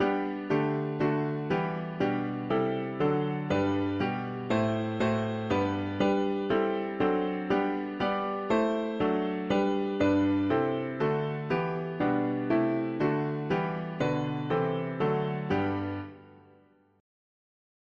Key: G major